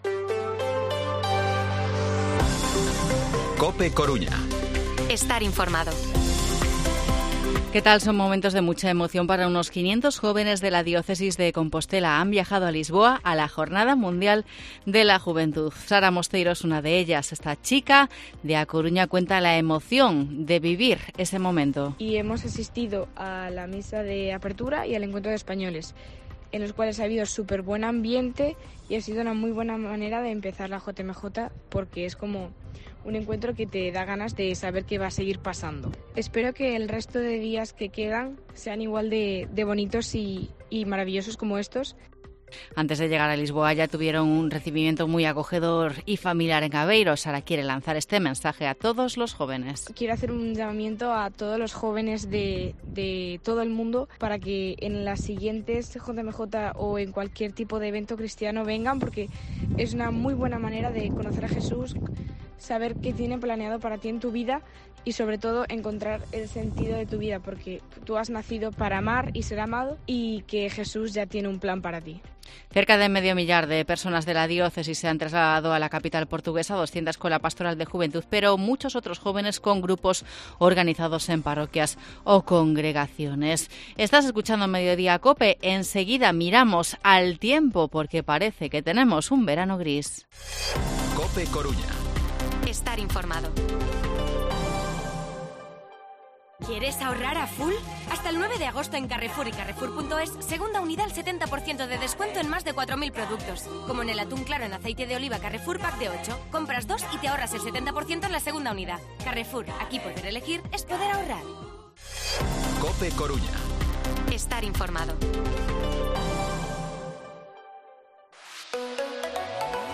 Informativo Mediodía COPE Coruña miércoles, 2 de agosto de 2023 14:20-14:30